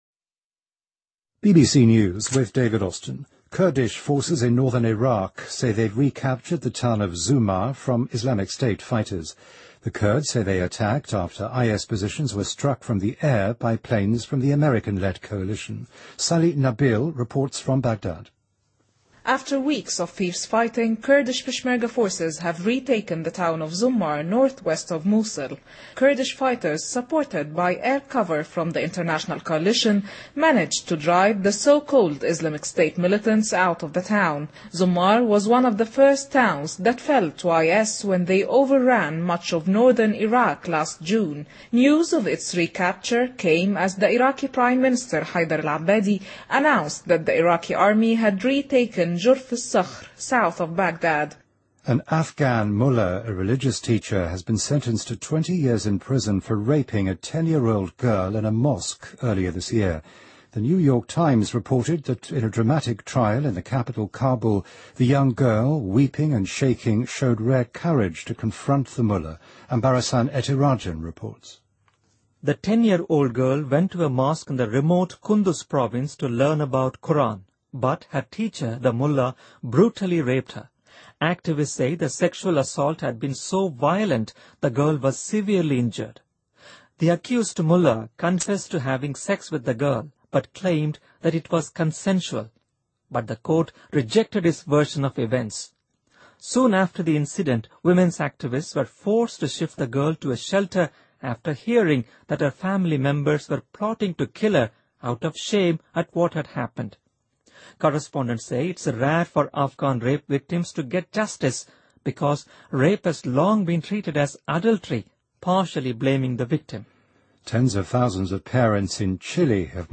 您的位置：BBC > BBC在线收听 > 10月新闻 > 最新BBC新闻